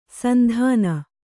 ♪ sandhāna